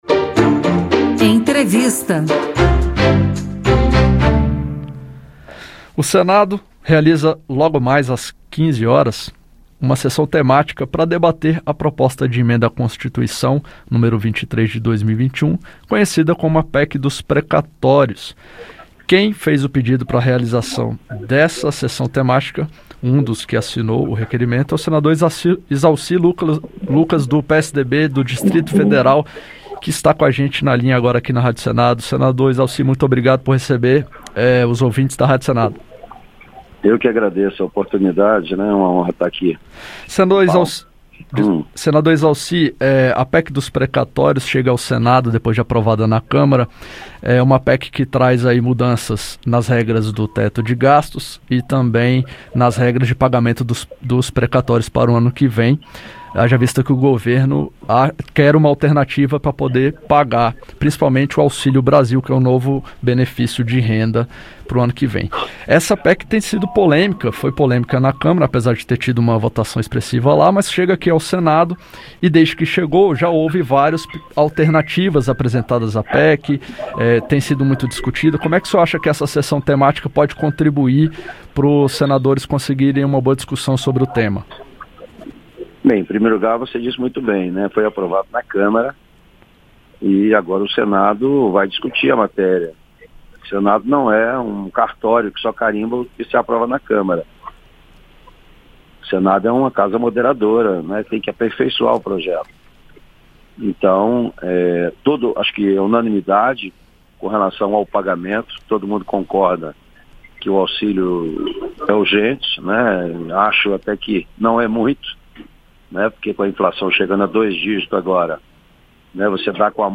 Aqui você escuta as entrevistas feitas pela equipe da Rádio Senado sobre os assuntos que mobilizam o país e sobre as propostas que estão sendo discutidas no Parlamento.